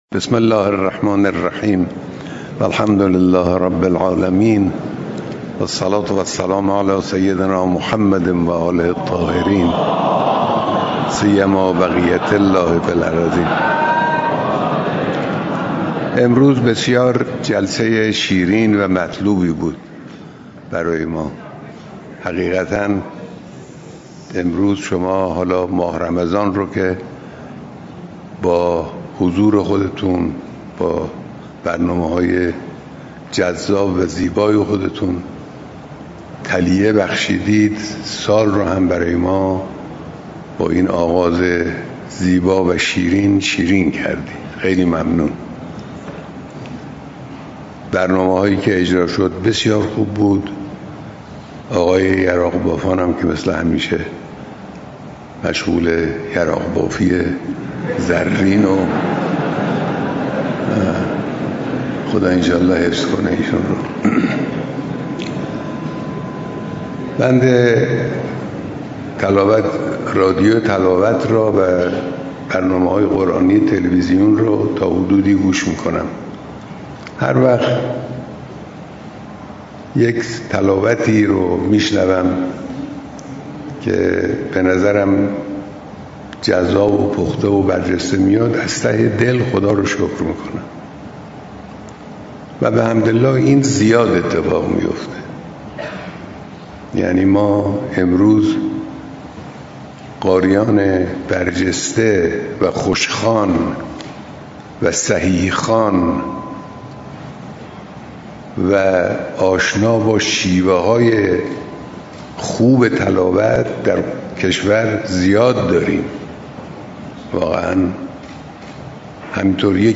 صوت | بیانات رهبر انقلاب در اولین روز ماه رمضان
رهبر معظم انقلاب اسلامی عصر امروز در اولین روز ماه مبارک رمضان و در محفل نورانی انس با قرآن که با حضور شماری از قاریان و اساتید ممتاز و فعالان قرآنی برگزار شد، آمیخته شدن مجالس تلاوت قرآن با ترجمه و تفسیر آیات را برای انتقال مفاهیم و مضامین قرآنی به مستعمان و اثرگذاری بر آنها، ضروری خواندند و متخصصان و فعالان قرآنی را به تلاش برای یافتن شیوه‌های این مسئله مهم فراخواندند.